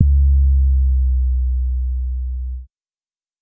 SB6 808 (5).wav